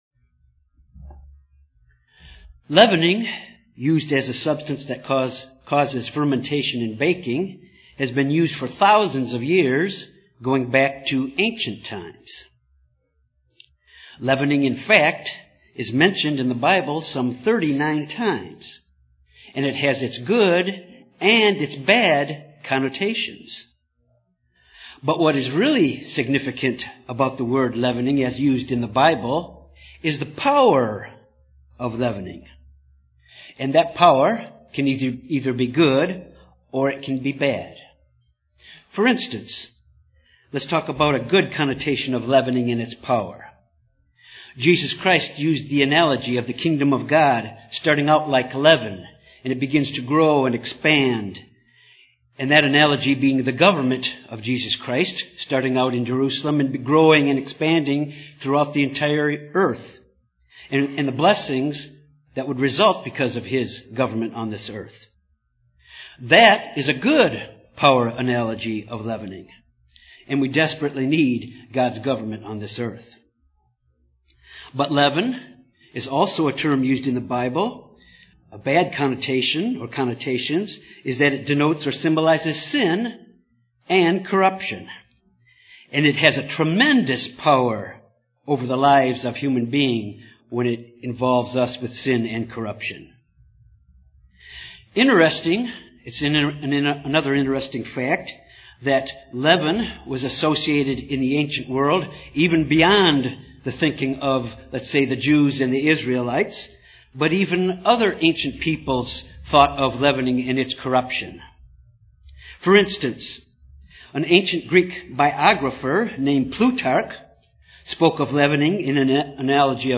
This sermon deals with understanding the type of leaven of the Pharisees and Suducees and taking steps to keep that type of leaven out of our lives